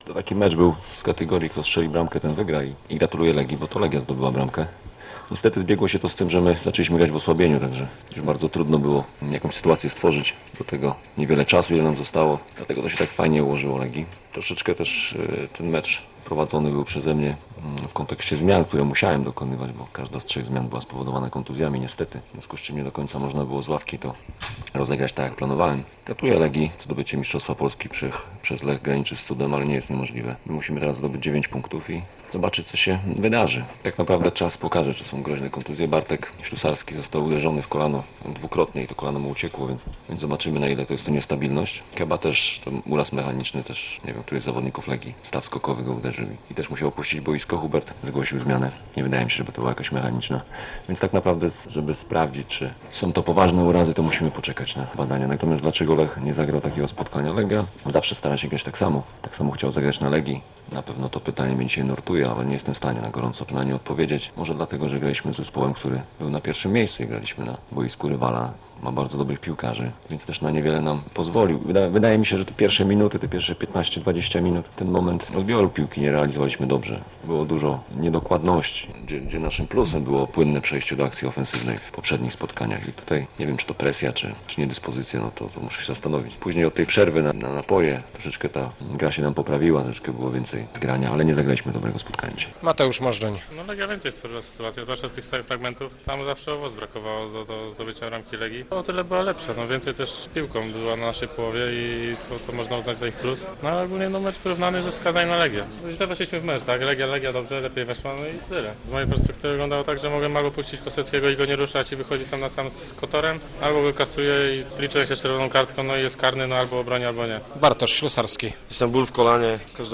sgcuw1p1m950uza_rozmowy-po-meczu-legia-lech.mp3